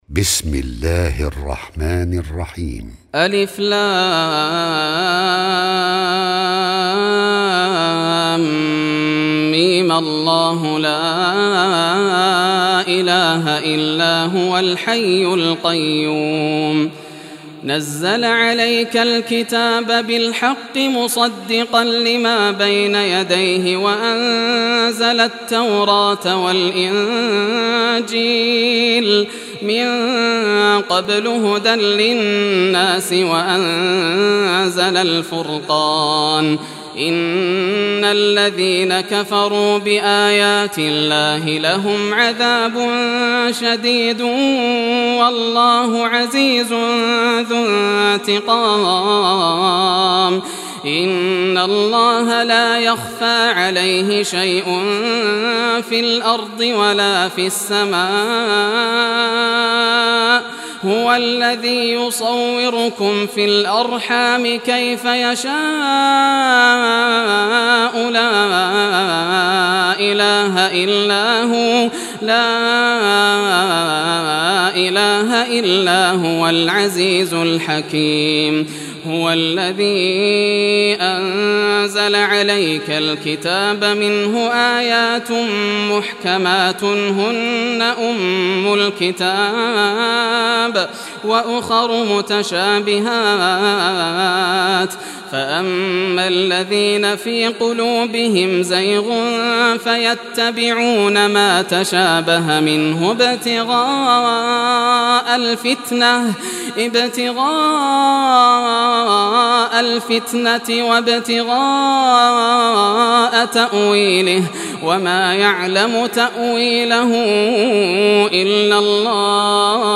Surah Al Imran Recitation by Yasser al Dosari
Surah Al Imran, listen or play online mp3 tilawat / recitation in Arabic in the beautiful voice of Sheikh Yasser al Dosari.